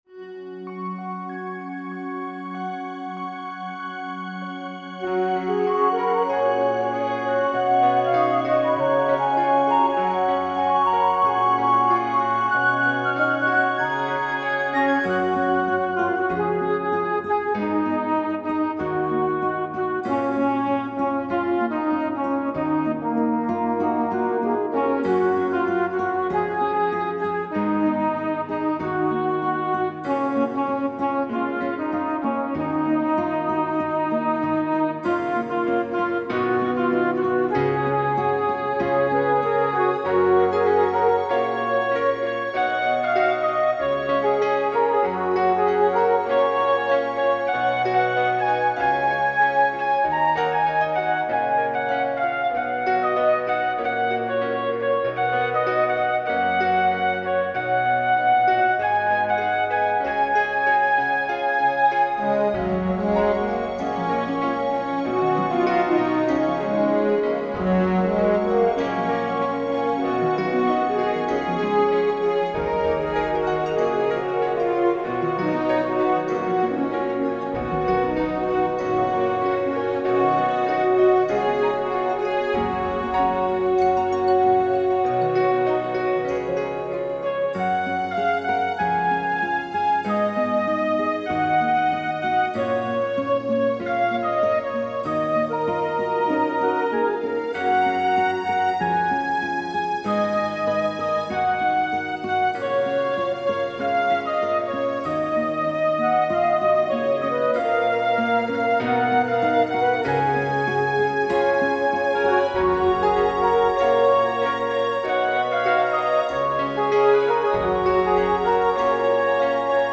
I listened to my song’s lilting melody and a feeling of nostalgia overwhelmed me.
First, we refined the original arrangement with some spectacular improvements, and then we created an instrumental by adding the melody line.
what-youve-meant-to-me-instrumental.mp3